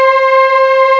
SMOOOVOX.wav